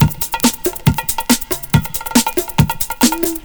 PERCLOOP2-L.wav